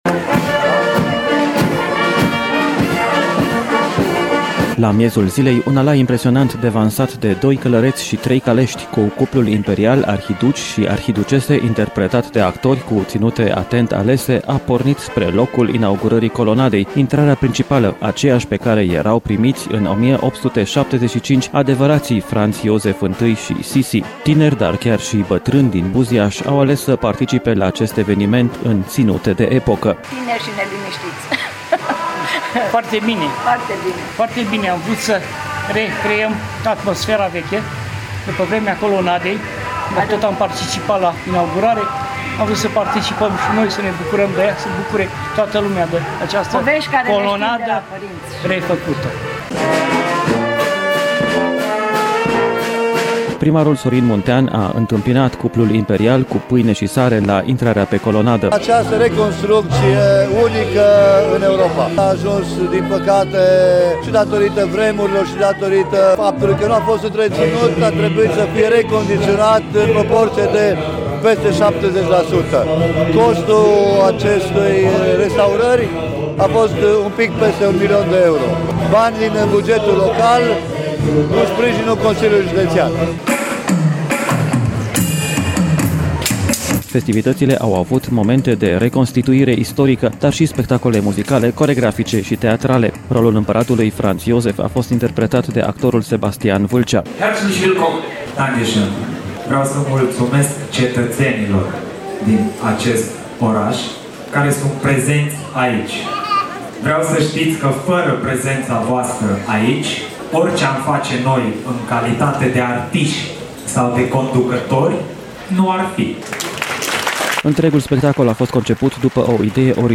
Festivitățile au avut momente de reconstituire istorică, dar și spectacole muzicale, coregrafice, teatrale.